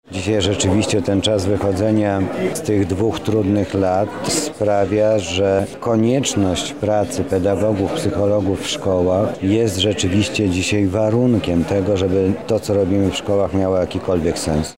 Dzisiaj odbyła się natomiast oficjalna część, w której udział wzięli przedstawiciele władz rektorskich, dziekańskich oraz samorządowych.
Potrzebę pracy i realizacji misji przez pedagogów i psychologów najbardziej pokazały nam lata pandemii– mówi Mariusz Banach, Zastępca Prezydenta do spraw Oświaty i Wychowania